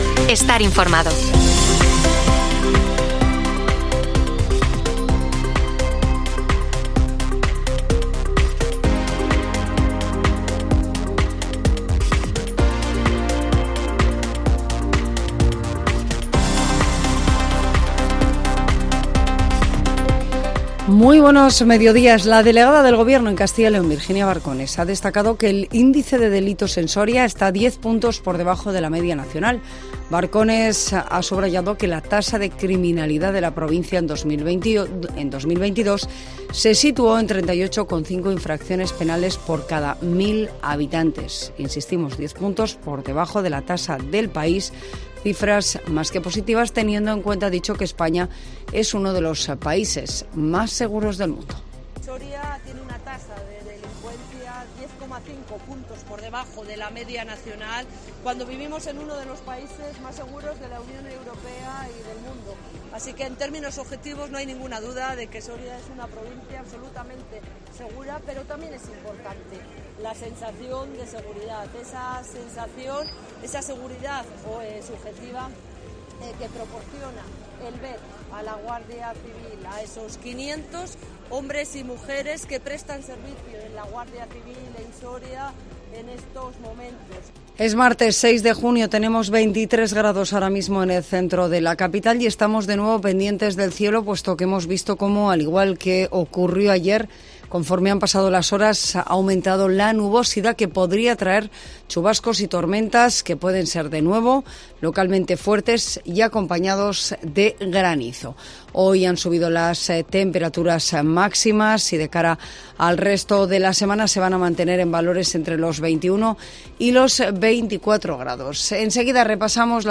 INFORMATIVO MEDIODÍA COPE SORIA 6 JUNIO 2023